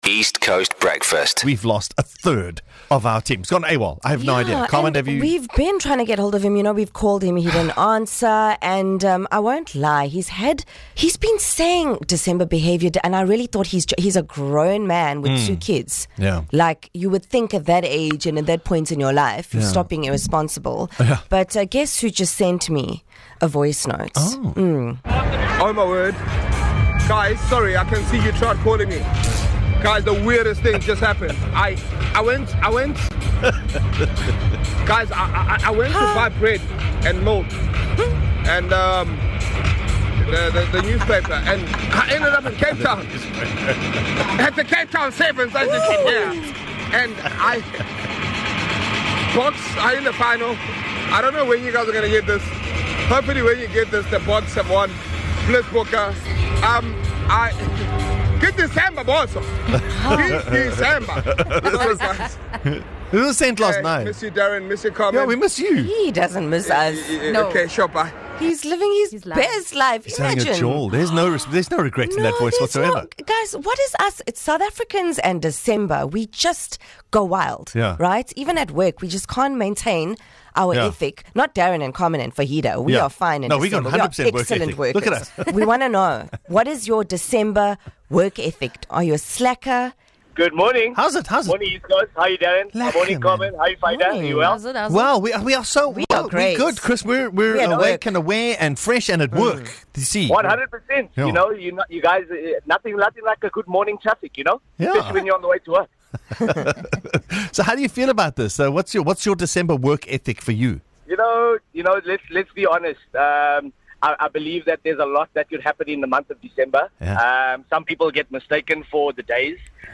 The East Coast Radio Breakfast Show is a fun, and hyperlocal radio show that will captivate and entertain you.
Tune in to the show for an energizing start to your day, accompanied by a fantastic selection of music that will keep you hooked.